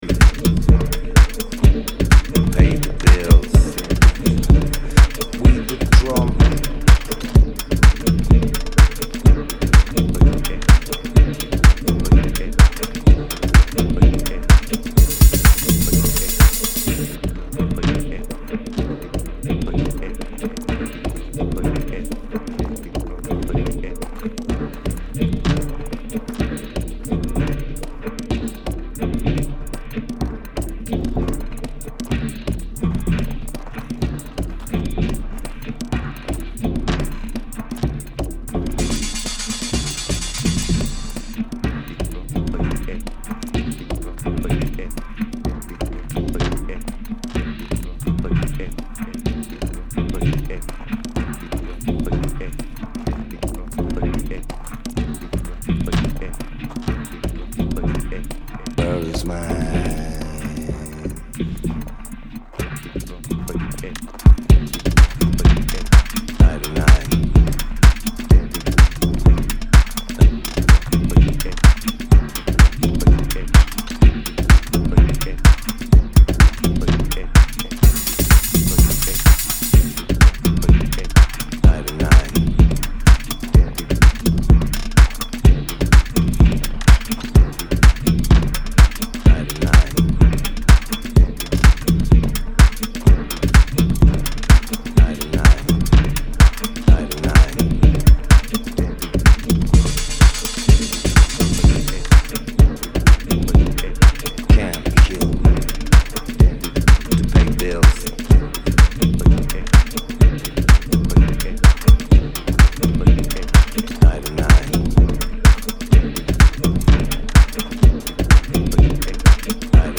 of hyponitism and minimal techno